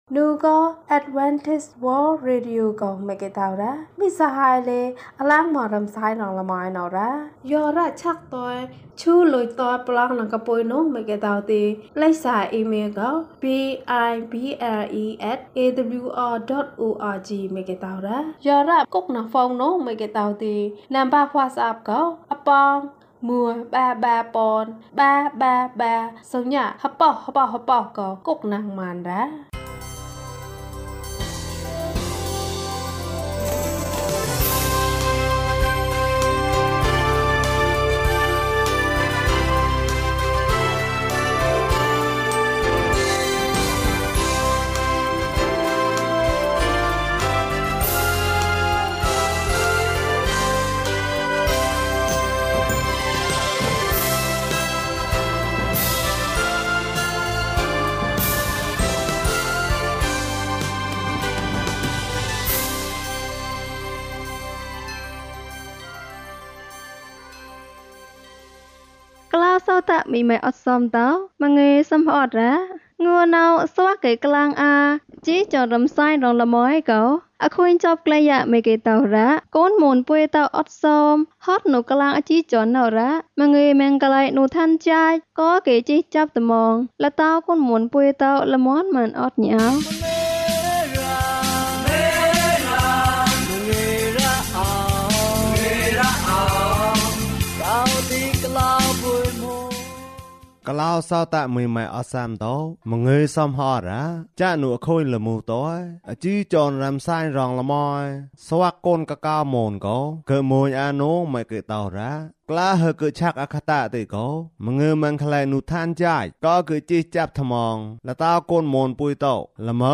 (ဒဒု်မဒးပ္ဍဲကဵုဂလာန်ပတှေ်) ကျန်းမာခြင်းအကြောင်းအရာ။ ဓမ္မသီချင်း။ တရားဒေသနာ။